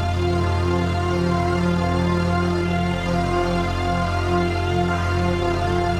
Index of /musicradar/dystopian-drone-samples/Non Tempo Loops
DD_LoopDrone2-E.wav